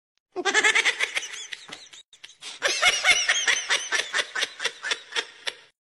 'The whimpering audio'